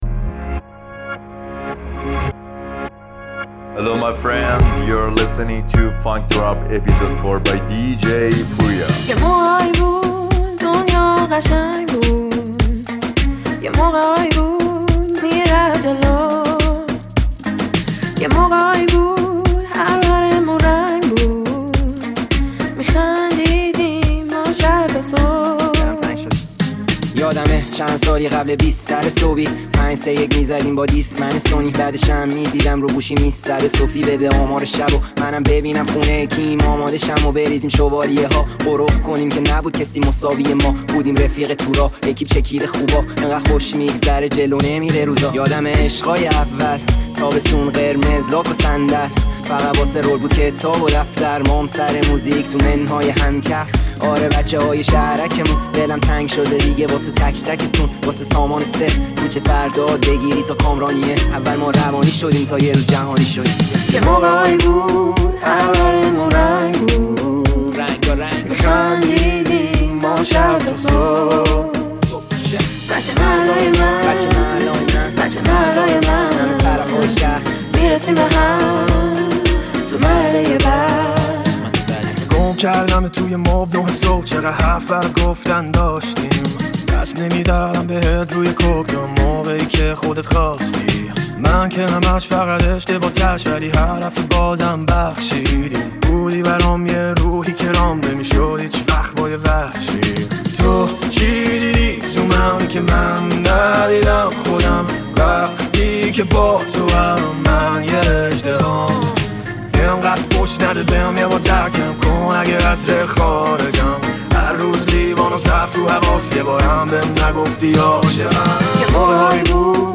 میکس بهترین موزیک های هیپ هاپ طولانی پشت سرهم